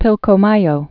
(pĭlkō-mäyō, pēl-)